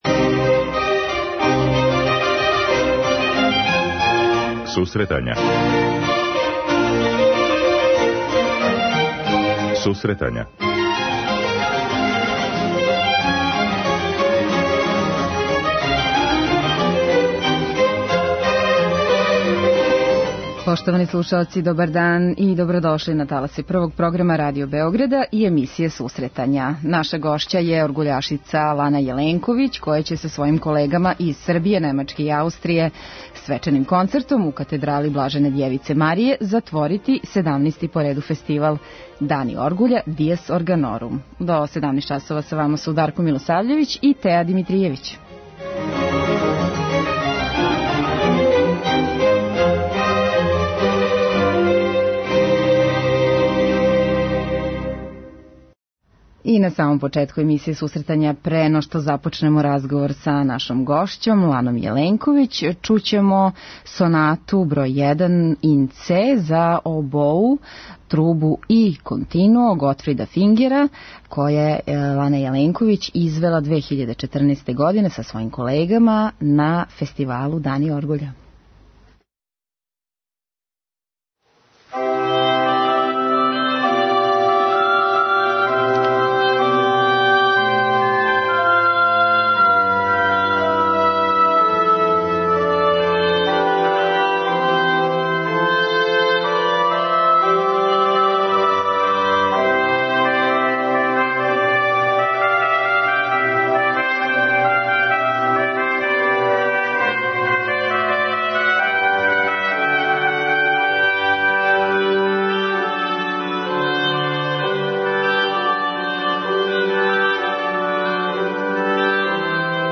преузми : 10.78 MB Сусретања Autor: Музичка редакција Емисија за оне који воле уметничку музику.